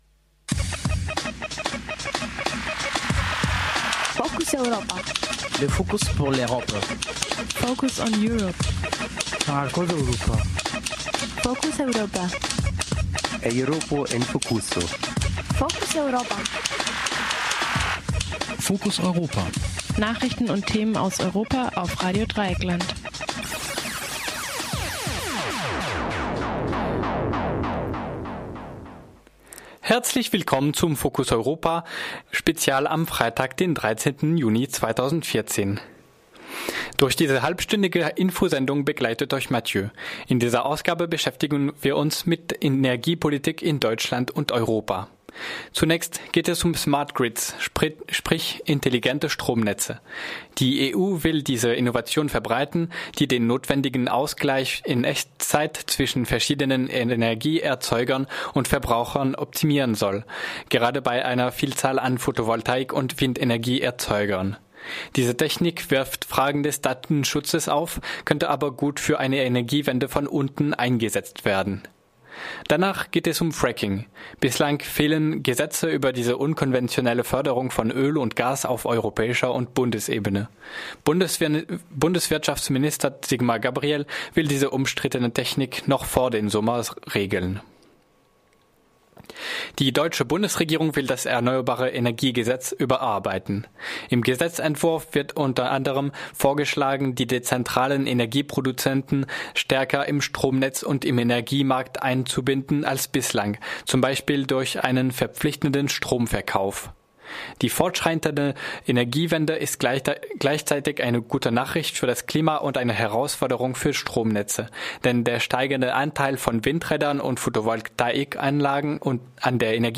In der Reihe "Focus Europa" erwarten Euch von Montag bis Freitag Interviews und Hintergrundberichte, die Ihr im Morgenradio oder im Mittagsmagazin hören könnt, und die Focus-Europa-Nachrichten um 9:30h im Morgenradio.